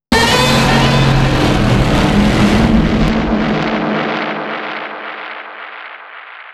Godzilla_Roar_Reborn.wav